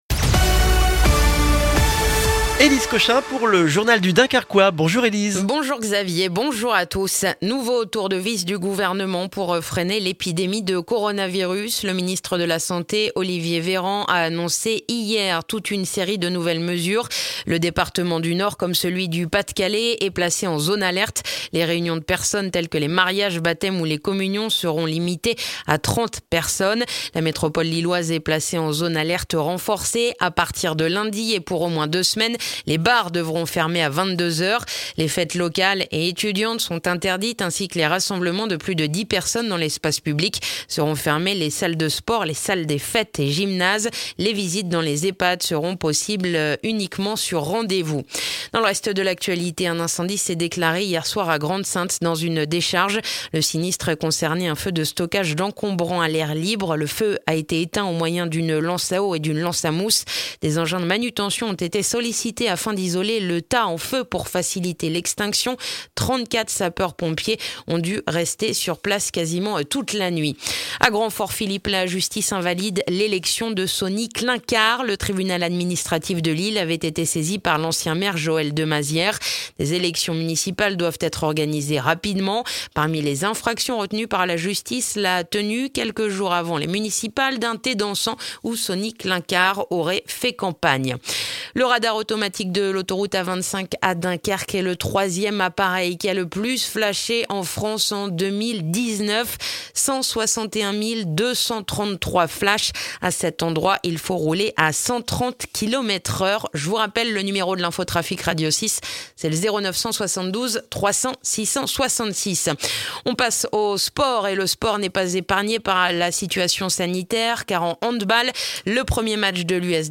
Le journal du jeudi 24 septembre dans le dunkerquois